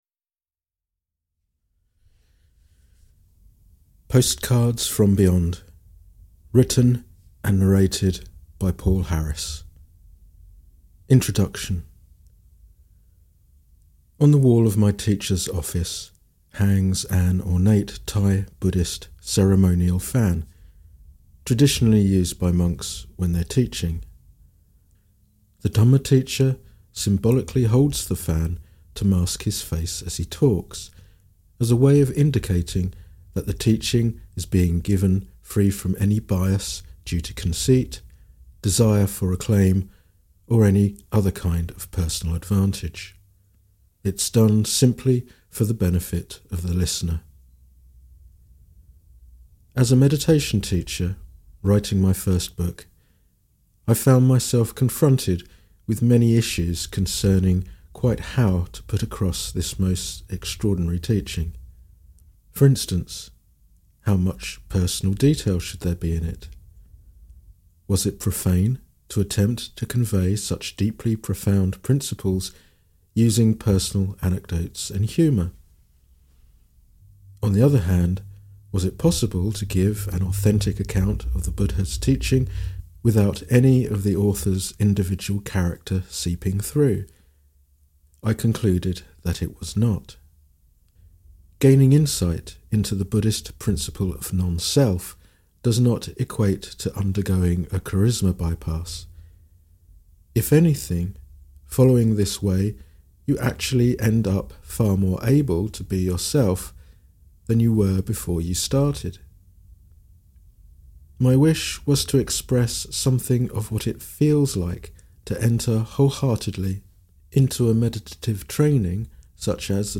Audio recording of the book "Postcards from Beyond"